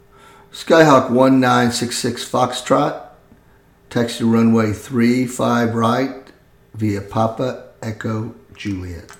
Aviation Radio Calls
08_GroundRunwayThreeFiveRightViaPapaEchoJuliet.mp3